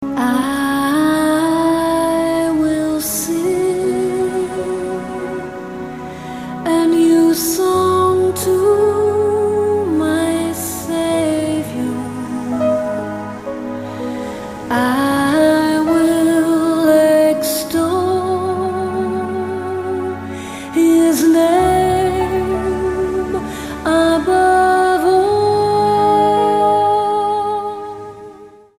STYLE: Celtic